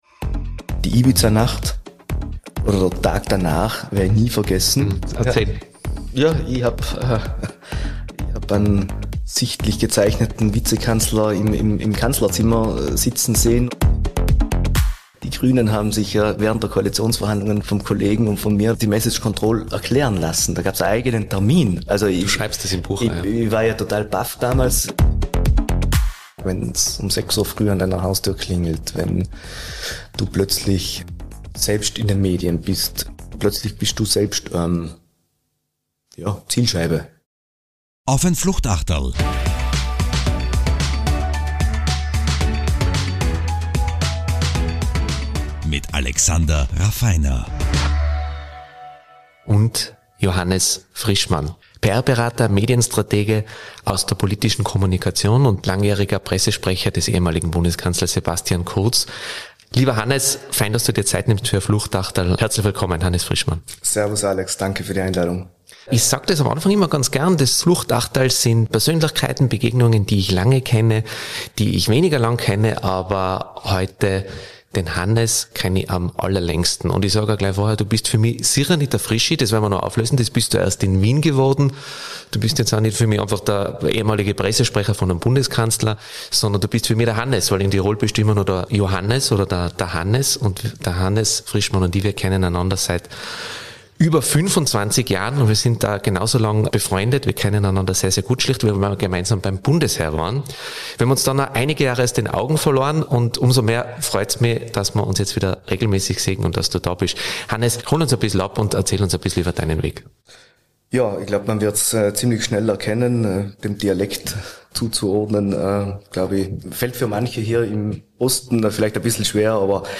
Es war persönlicher und direkter - und ein wenig „Tiroler Schmäh“ war auch dabei.